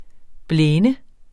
Udtale [ ˈblεːnə ]